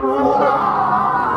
maybe boss death.wav